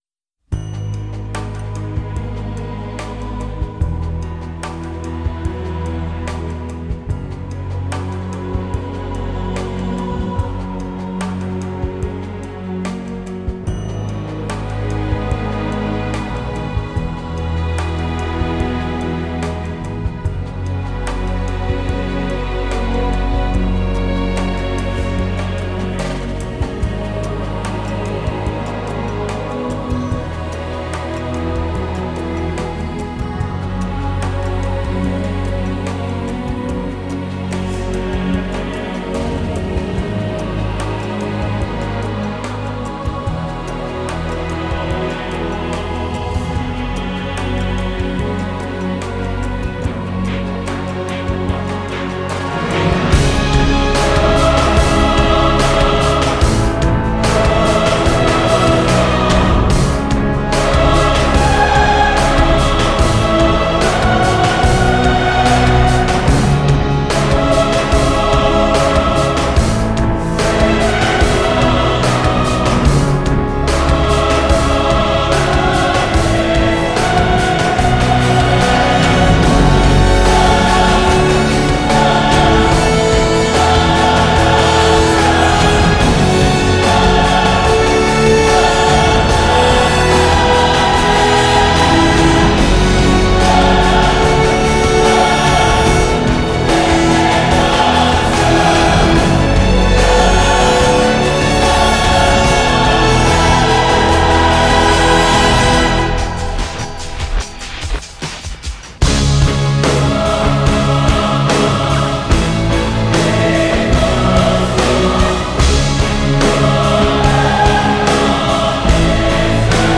史诗气势音乐